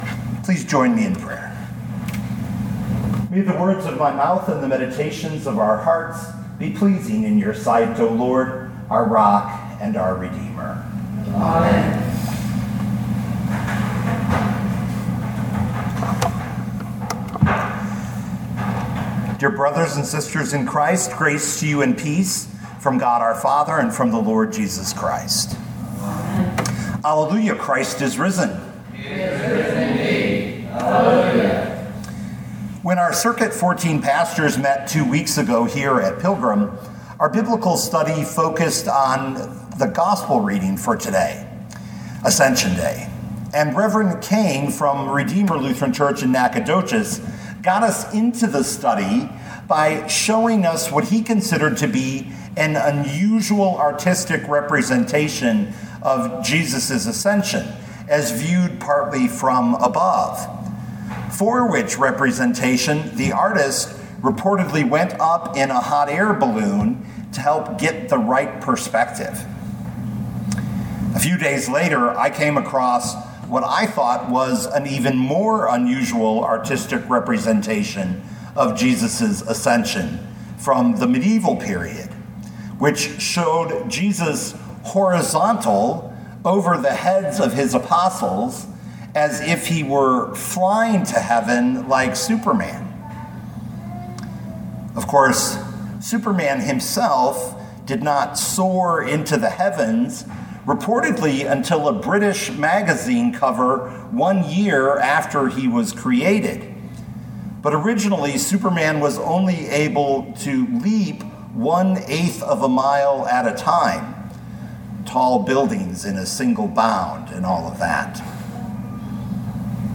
2024 Ephesians 1:15-23 Listen to the sermon with the player below, or, download the audio.